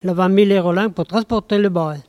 Localisation Saint-Urbain
Langue Maraîchin
Catégorie Locution